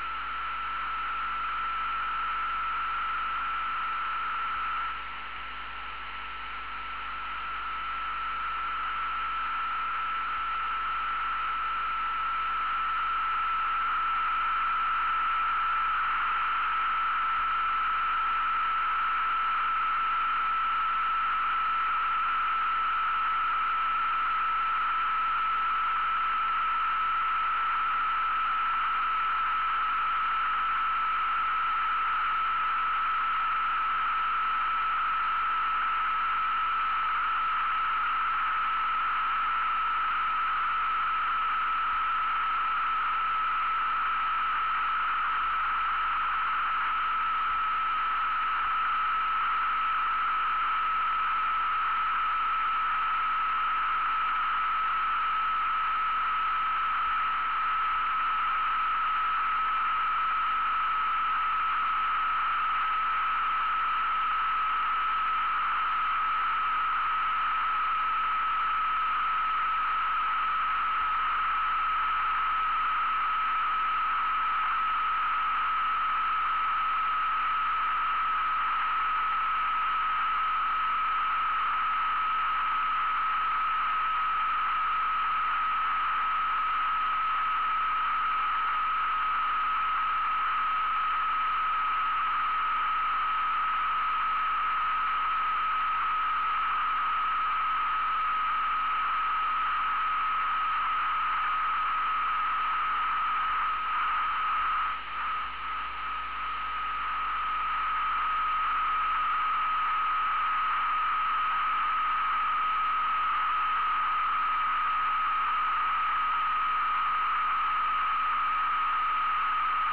Note the extremely slow rate of change of frequency. The absolute Doppler shift is -247 kHz, with the spacecraft 36.6 million km from Earth.